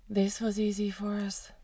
Voice quality samples (English regular, English irregular,
English breathy)
female_breathy.wav